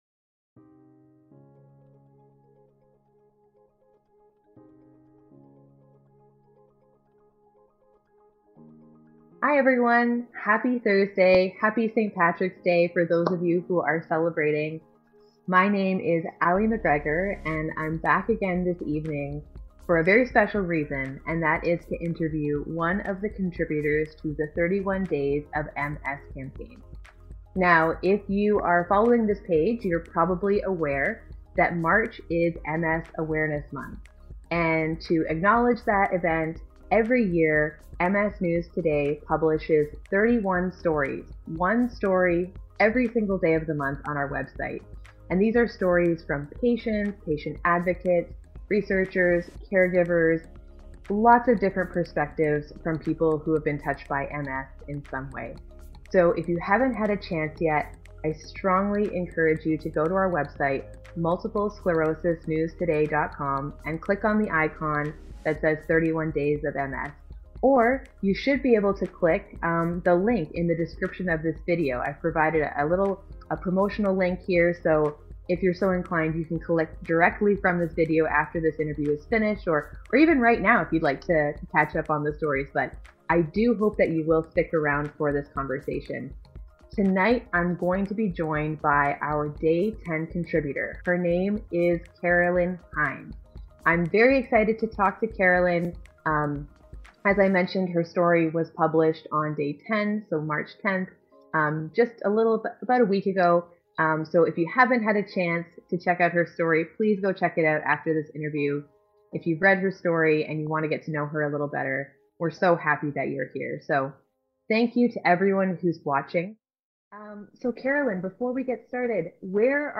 Facebook Live